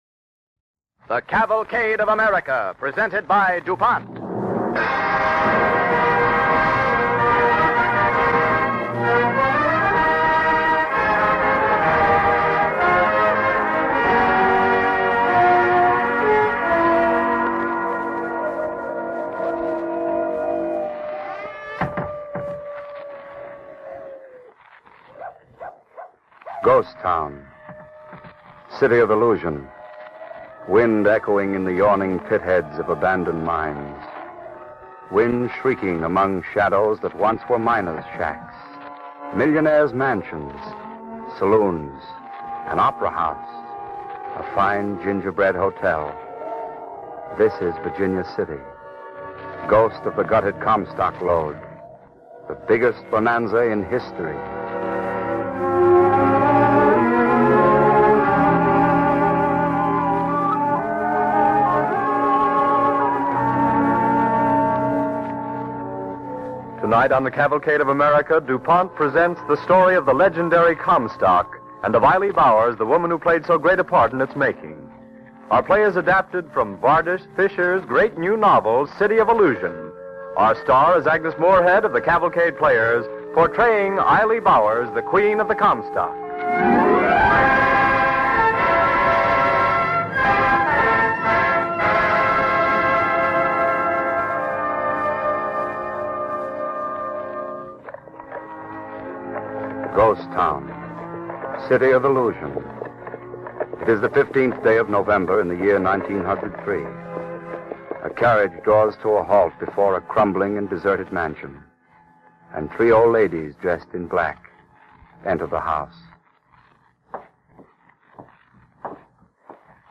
Cavalcade of America Radio Program